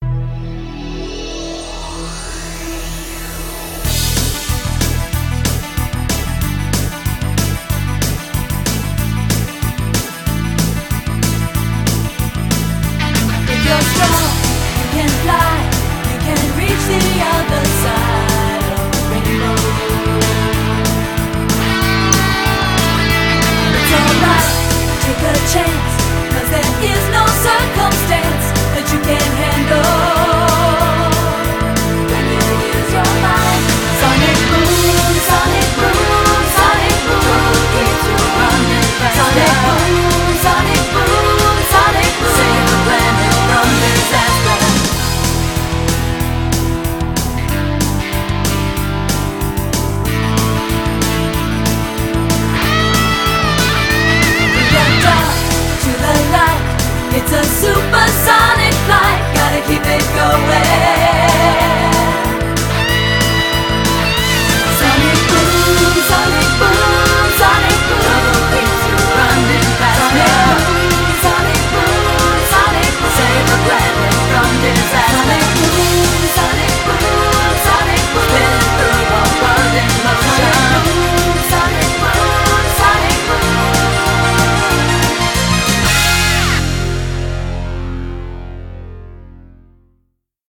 BPM187-187
Audio QualityCut From Video